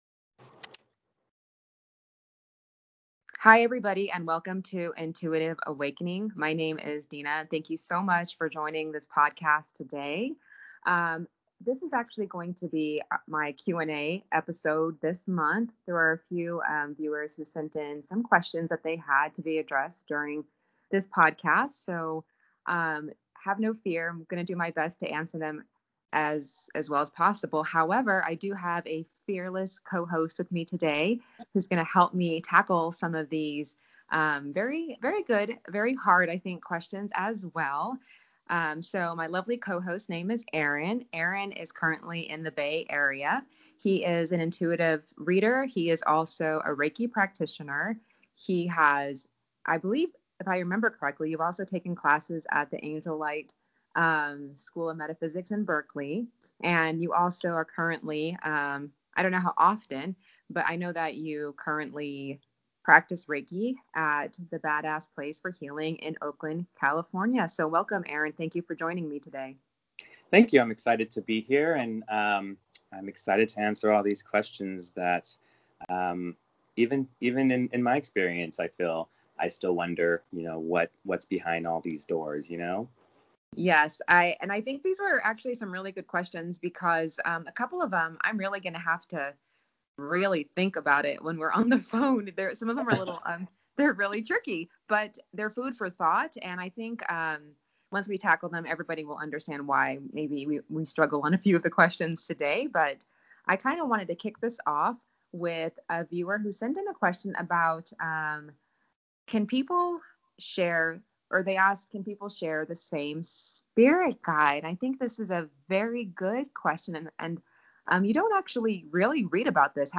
Monthly Q and A!
In this episode, I am joined by a lovely co-host, to address your questions.